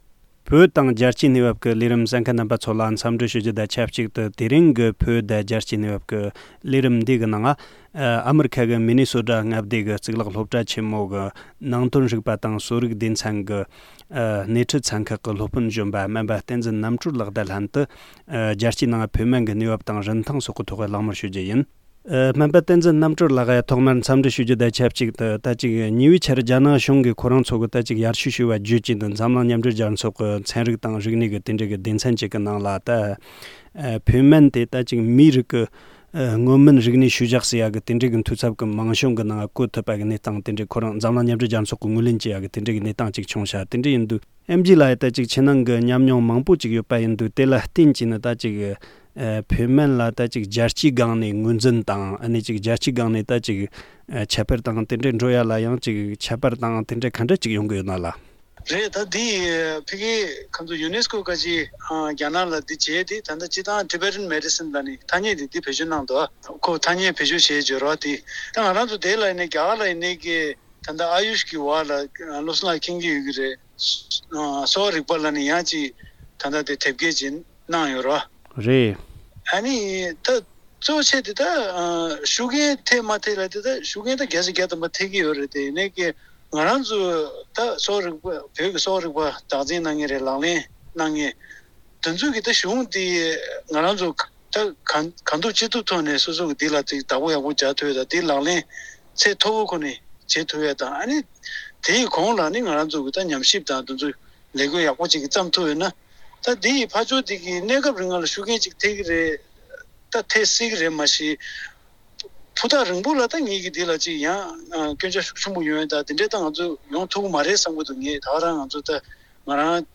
གླེང་བ།